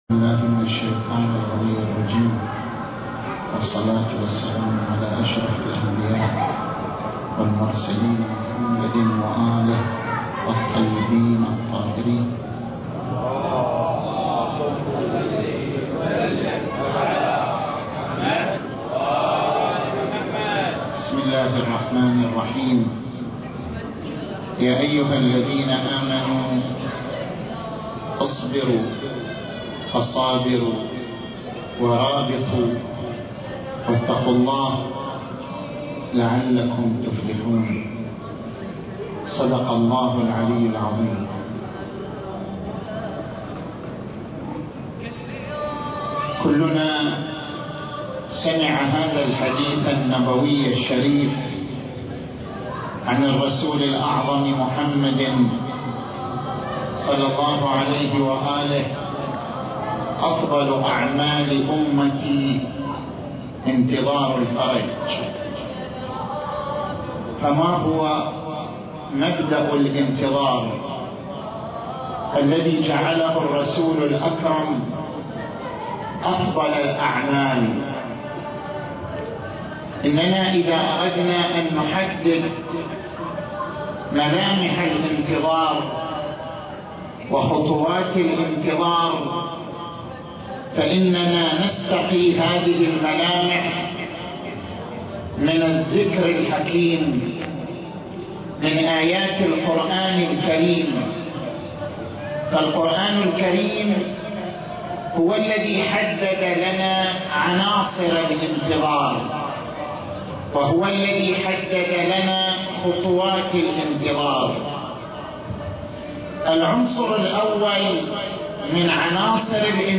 المكان: مسجد المسألة